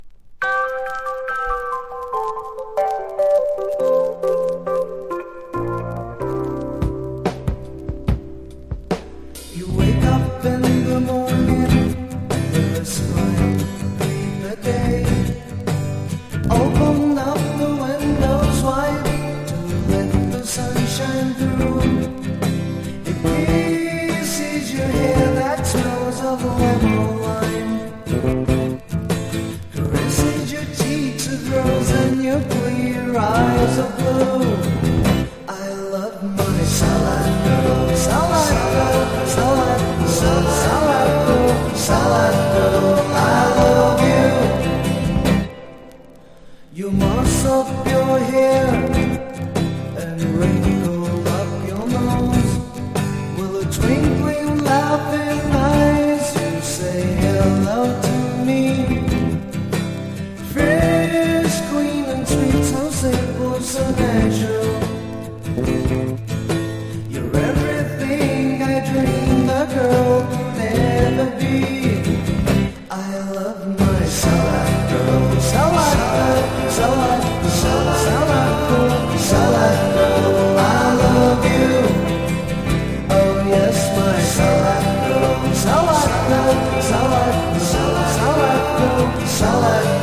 60-80’S ROCK# POP